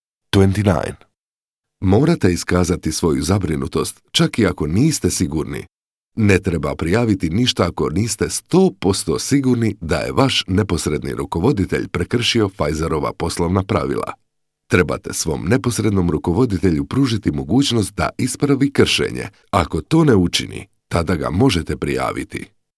Im croatian native speaker warm-intimate voice and Croatian literary pronunciation
Sprechprobe: eLearning (Muttersprache):